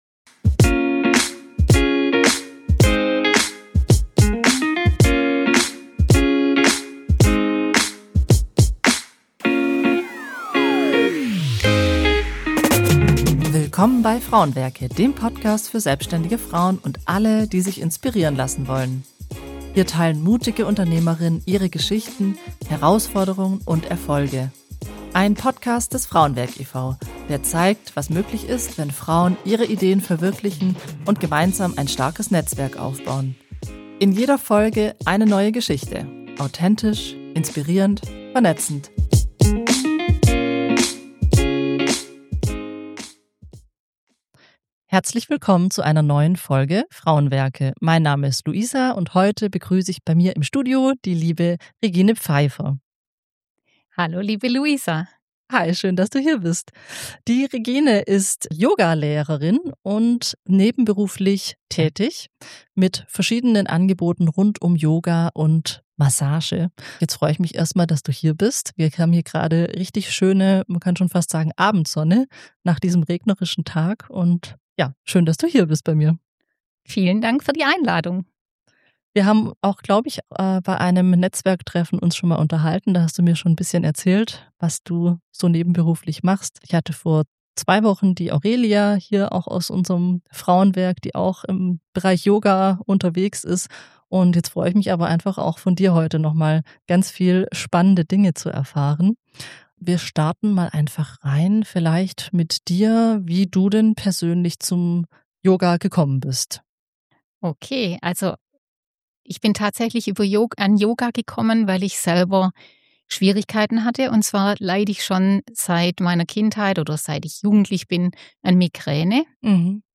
Außerdem erzählt sie, wie ihr Angebot „Yoga & Wandern" entstanden ist und warum sie das Beste aus Bewegung in der Natur und innerer Stille miteinander verbindet. Ein warmherziges Gespräch über einen langen, leisen Weg zu sich selbst – und darüber, was passiert, wenn man einfach mal in eine Stunde reinschnuppert und plötzlich nicht mehr aufhören möchte.